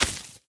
Minecraft Version Minecraft Version snapshot Latest Release | Latest Snapshot snapshot / assets / minecraft / sounds / entity / leashknot / break3.ogg Compare With Compare With Latest Release | Latest Snapshot